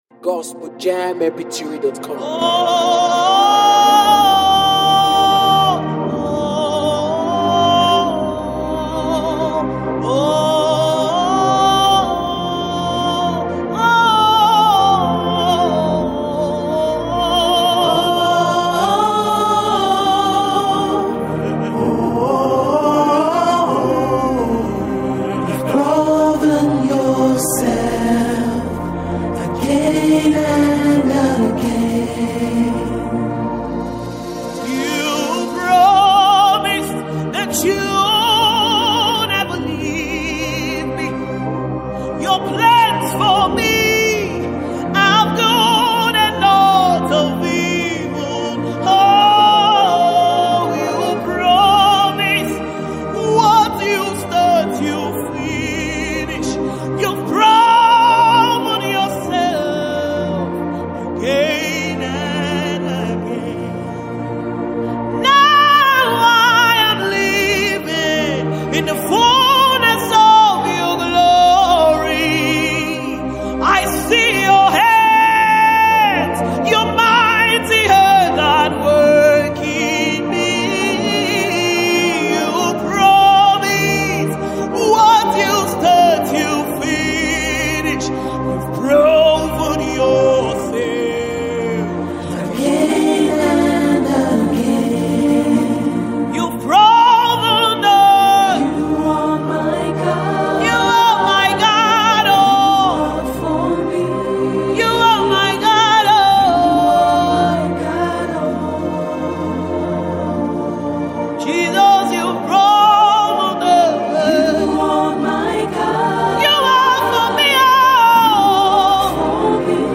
gospel worship song